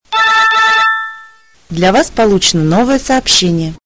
Главная » Рингтоны » SMS рингтоны